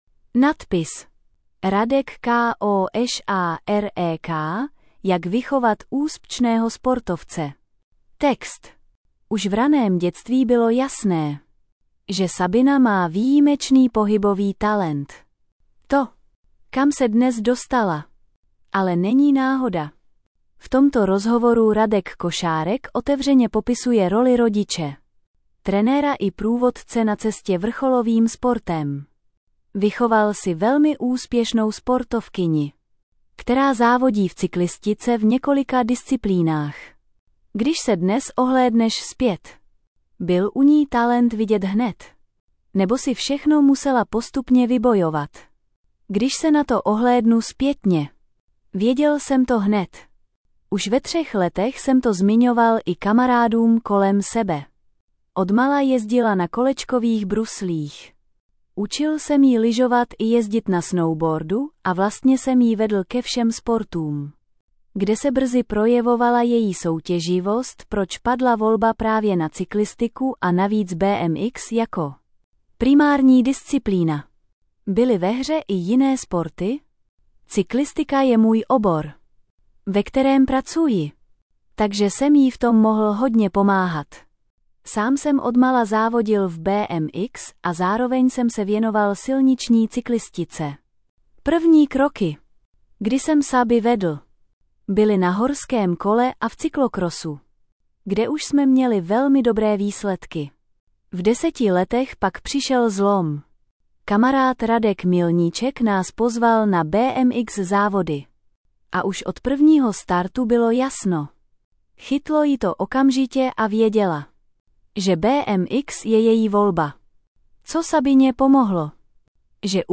Rozhovory Audio verze Poslech článku Otevřít audio Tvůj prohlížeč nepodporuje přehrávání audia.